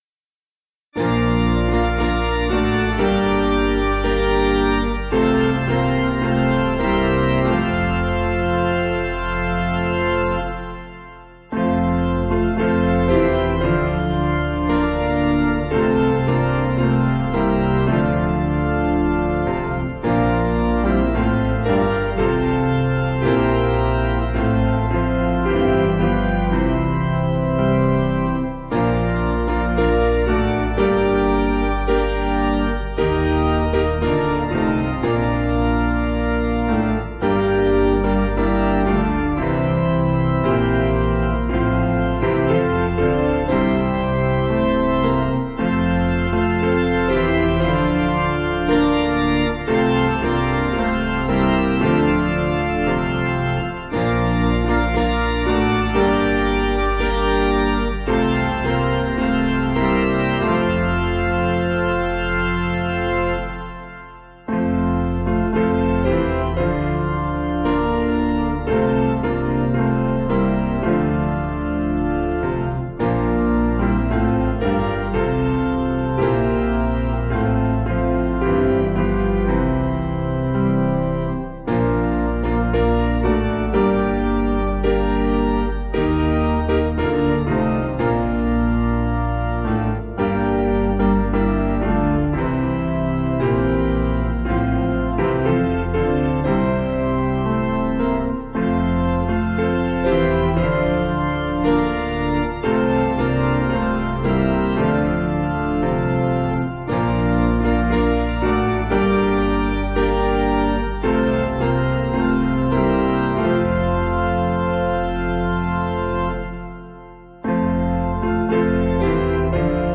Meter: 6.5.6.5
Key: G Major